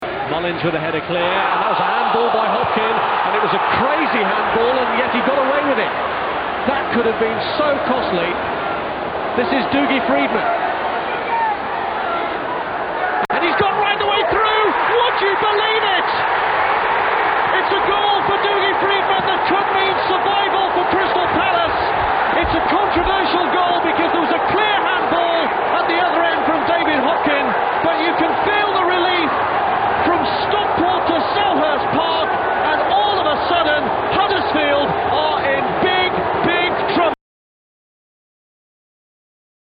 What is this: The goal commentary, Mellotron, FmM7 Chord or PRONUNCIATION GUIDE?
The goal commentary